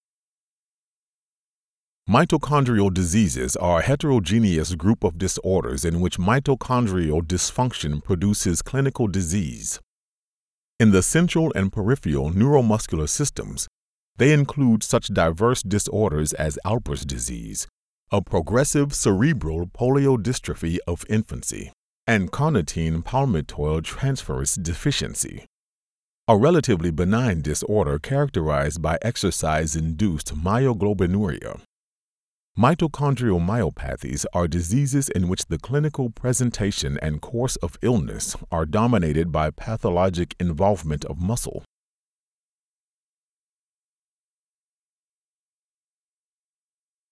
Male
Adult (30-50), Older Sound (50+)
Medical Narrations
1115Medical_Demo.mp3